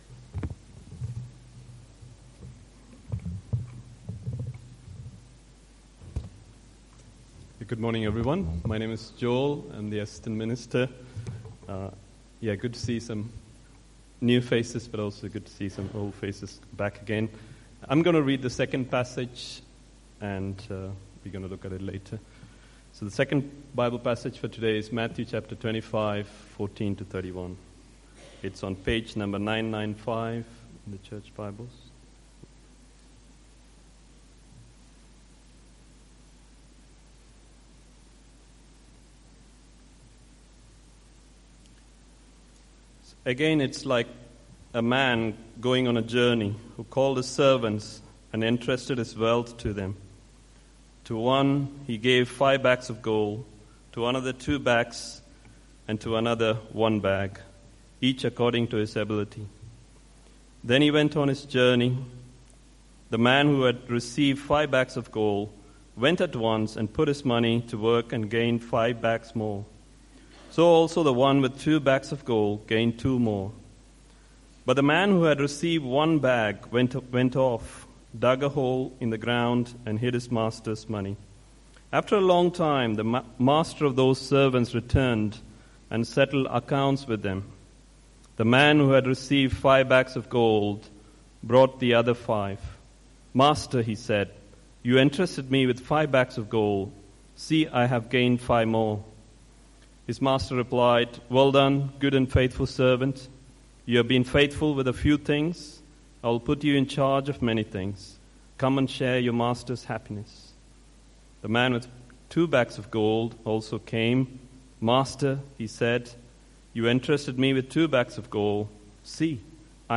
Passage: Matthew 25:14-31 Service Type: Sunday Morning